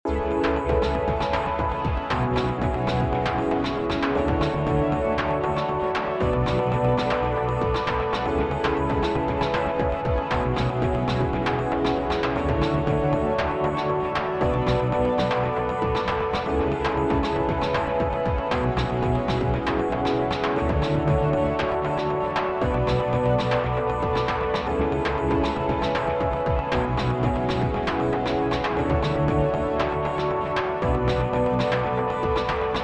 short loop cyberpunk anime future beat glitch synth loop